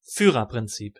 Ääntäminen
US : IPA : /ə.ˈbɪl.ə.ti/